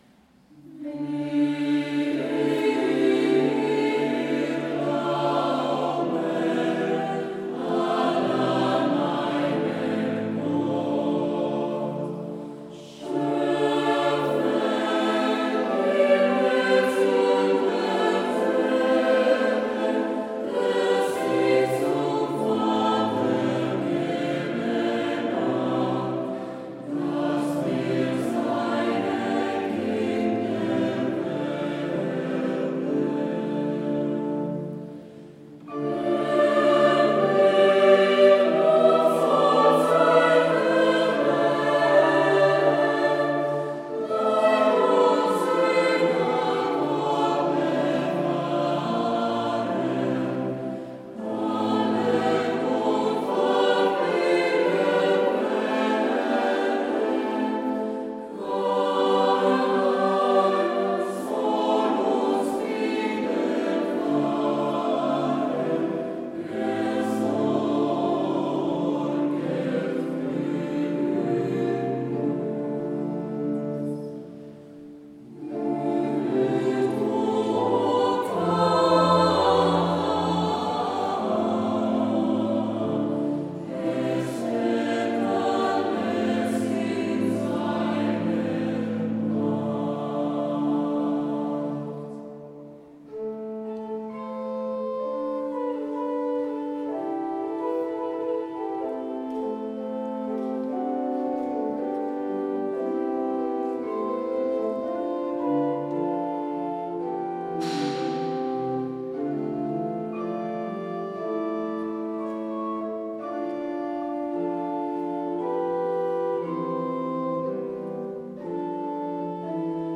Luogo esecuzioneParma
GenereCori
registrazione dal vivo